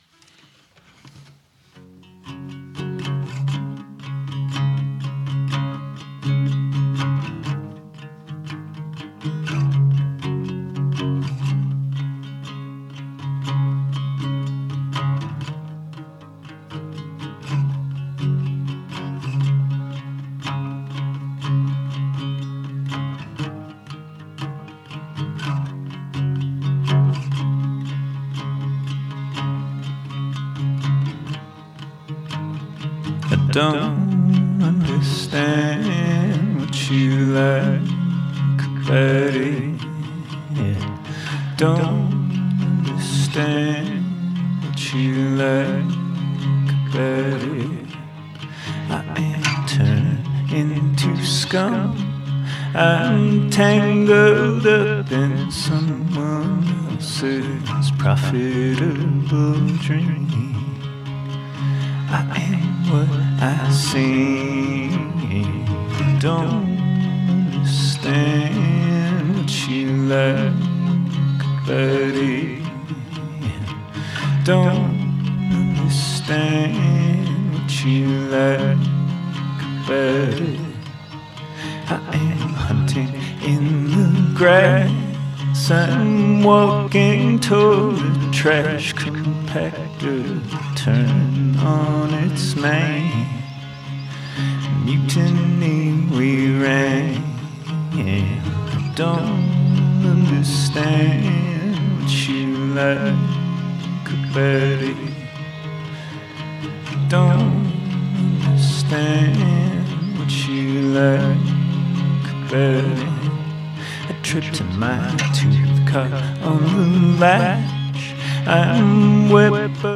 枯れた味わいを忍ばせた風変りのフォーク・ソングに、エレクトロニクスやノイズなどを絡ませた傑作！